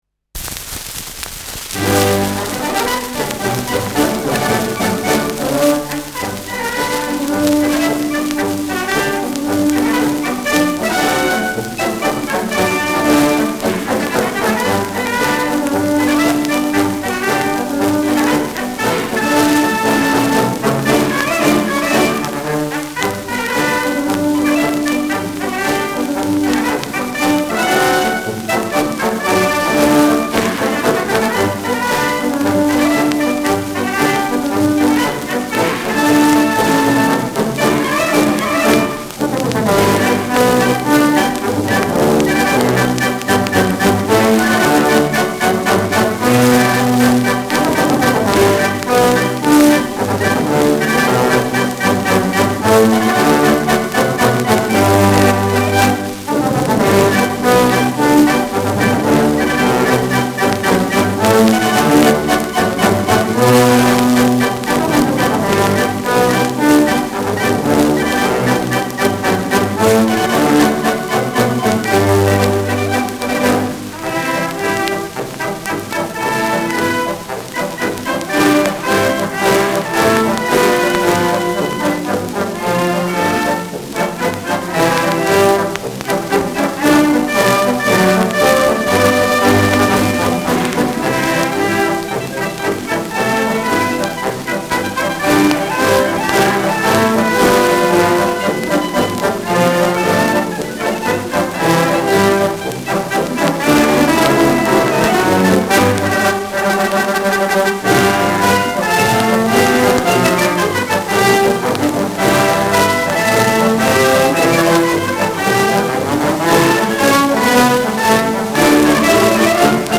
Schellackplatte
präsentes Rauschen : präsentes Knistern : leichtes Leiern : „Schnarren“ : gelegentliches Knacken